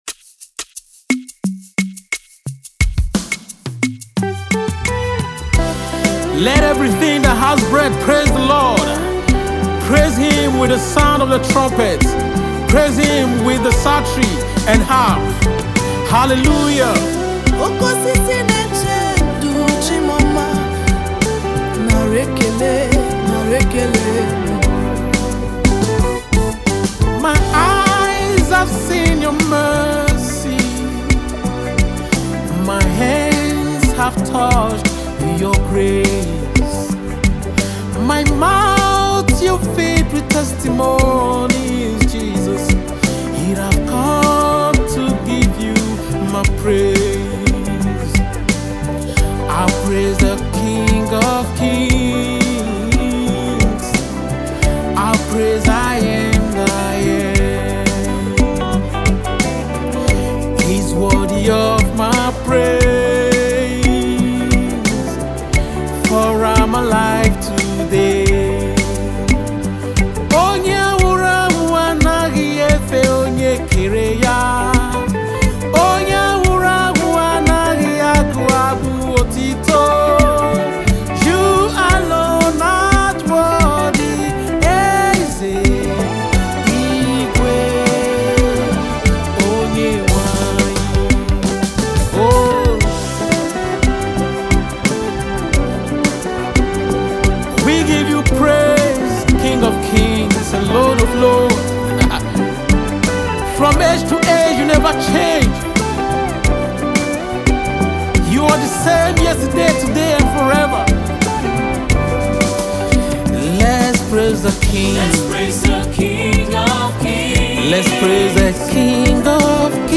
Indigenous Gospel music minister
song of gratitude